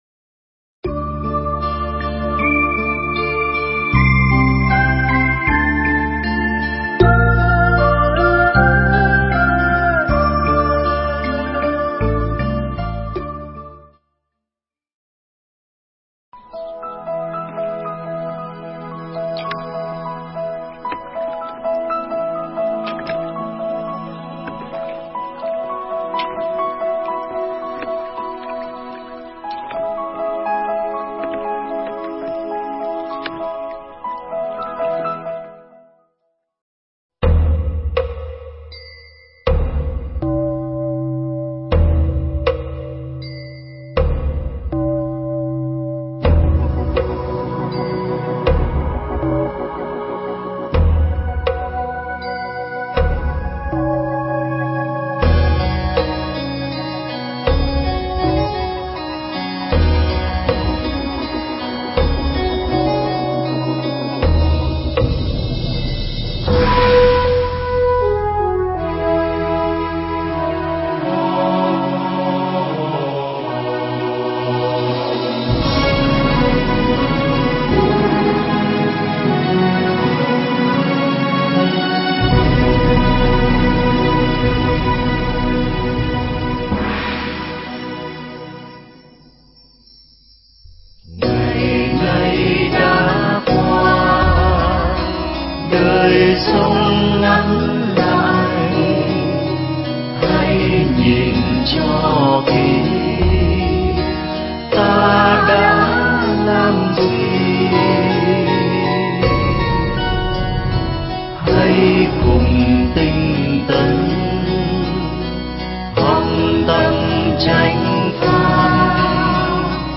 Giảng Kinh Duy Ma Cật Phẩm Phật Đạo